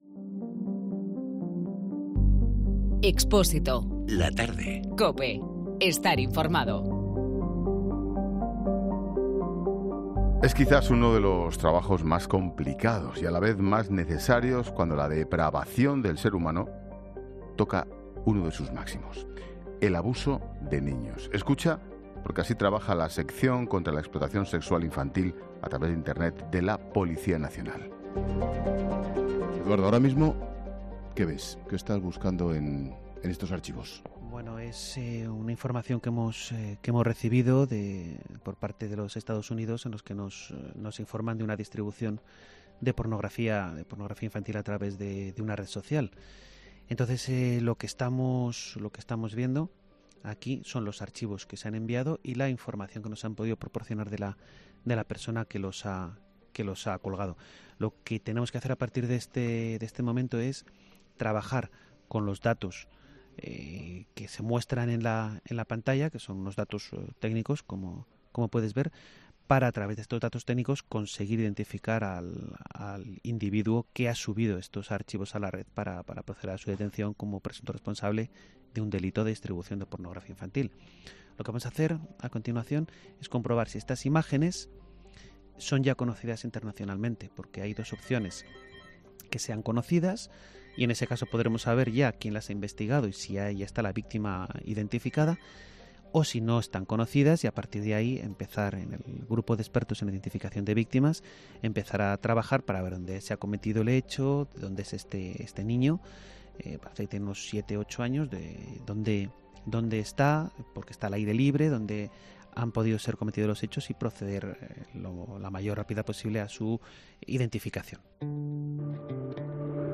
La sección contra la explotación sexual infantil en Internet de la Policía Nacional trabaja a diario con esto. El equipo de 'La Tarde' ha pasado unas horas con el equipo encargado de tratar estos asuntos en la Policía.
ESCUCHA EL REPORTAJE COMPLETO EN 'LA TARDE'